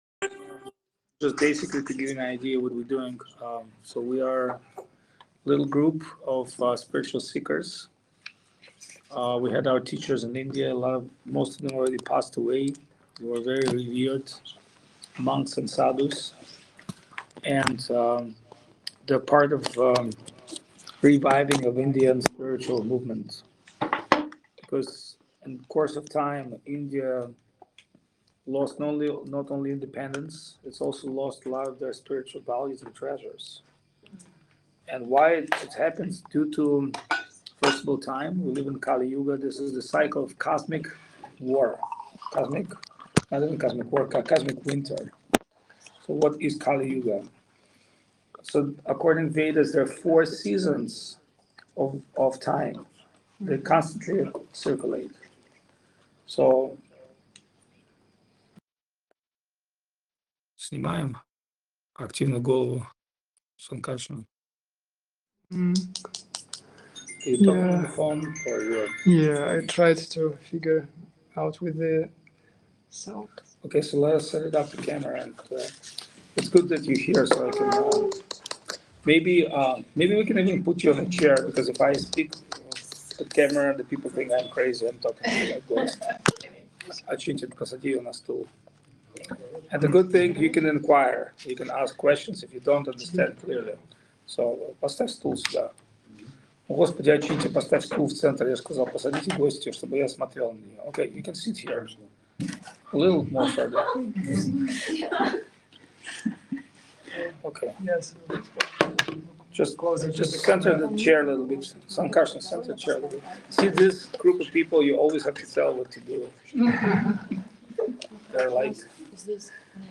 Chiang Mai, Thailand
Лекции полностью
Лекции на английском (без последовательного или синхронного перевода на русский язык)